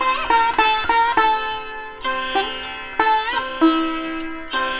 100-SITAR2-R.wav